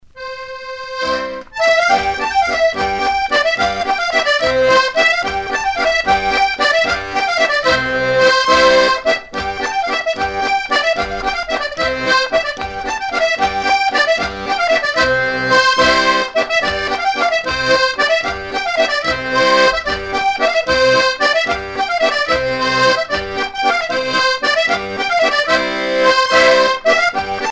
Région ou province Morvan
danse : bourree
Pièce musicale éditée